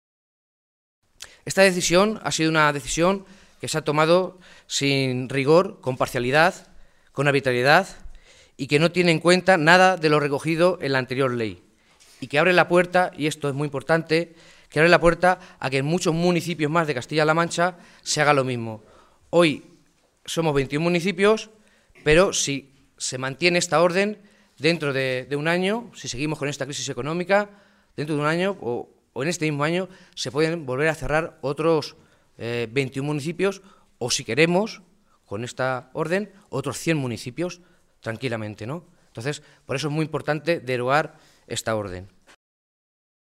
Rueda de prensa de los distintos alcaldes socialistas afectados por el cierre de los PAC en la Región
Cortes de audio de la rueda de prensa
Audio Alcalde de Tembleque-3